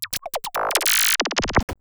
Glitch FX 13.wav